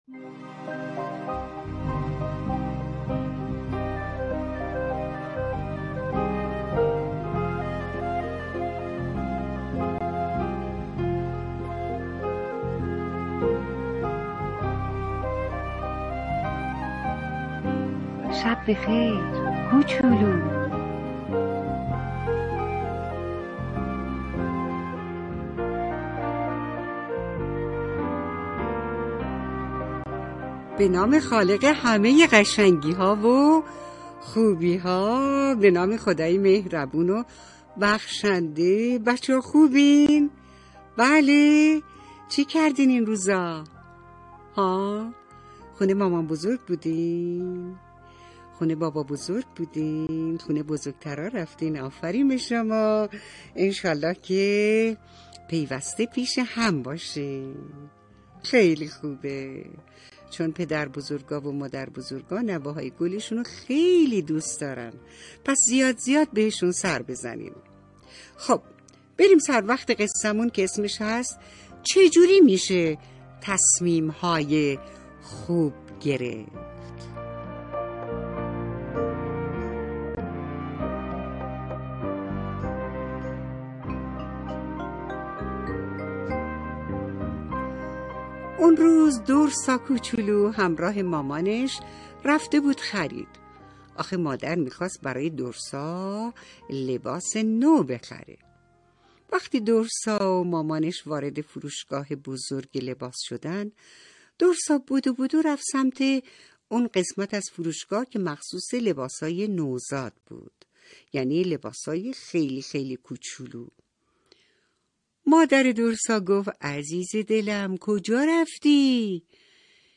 قصه صوتی کودکانه